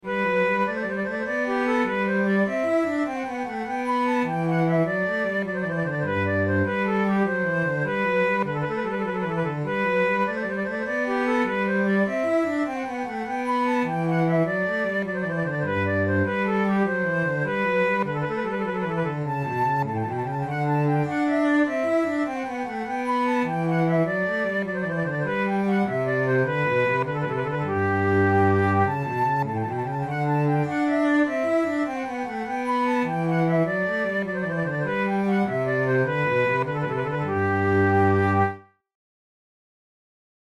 This gavotte is the third movement of the fourth of the six Op. 7 flute sonatas with bass accompaniment by French flutist and composer Jean-Daniel Braun, published in Paris in 1736.
Categories: Baroque Gavottes Sonatas Written for Flute Difficulty: intermediate